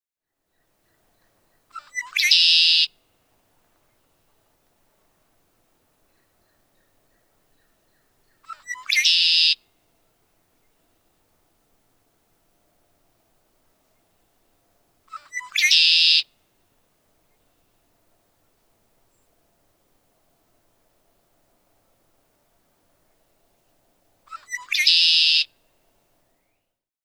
Red-winged Blackbird | Hunterdon Art Museum
red-winged-blackbird.mp3